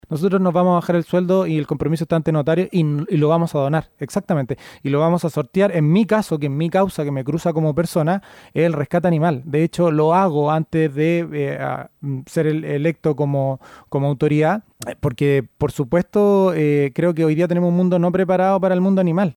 En entrevista con Radio Bío Bío, el diputado electo por el distrito 7 y ex candidato a alcalde de Valparaíso, Juan Marcelo Valenzuela, dio a conocer que en la interna se generó un acuerdo en el que los parlamentarios donarán un millón de pesos de su sueldo a causas benéficas.